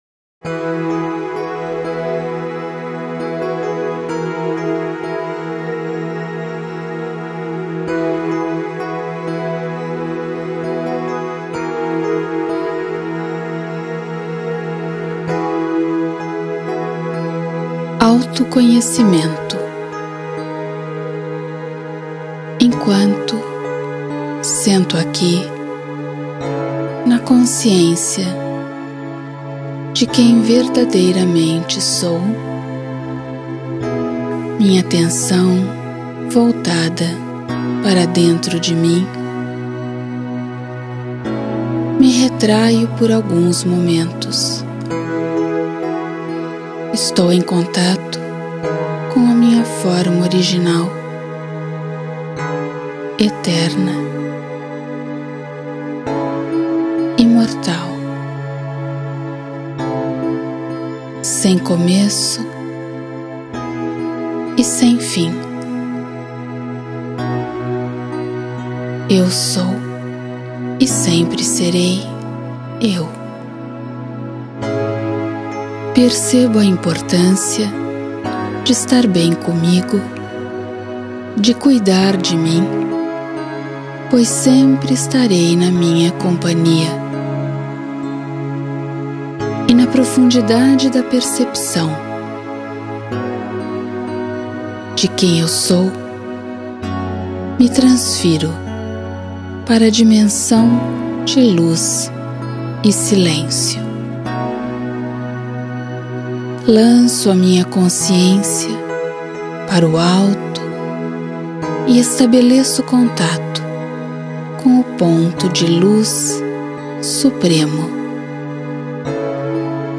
Audio: Autoconhecimento (Meditação orientada)